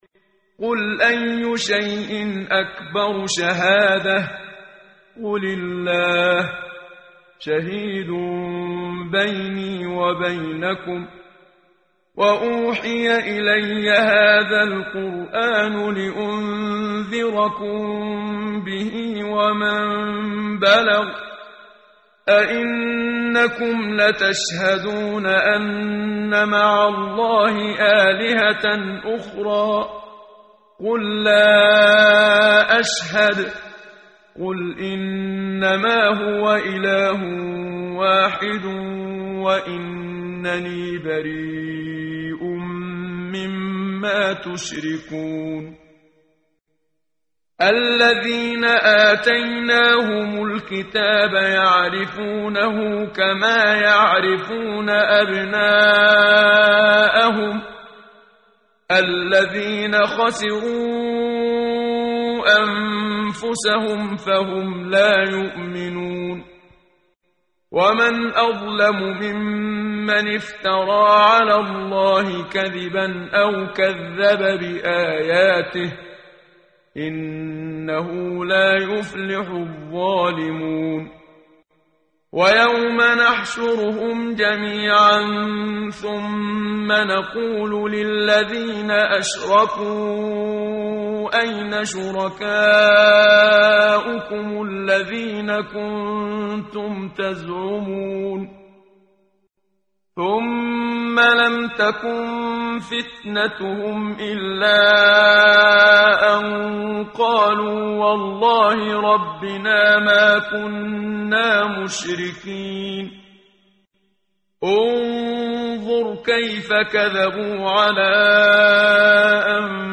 ترتیل صفحه 130 سوره مبارکه انعام (جزء هفتم) از سری مجموعه صفحه ای از نور با صدای استاد محمد صدیق منشاوی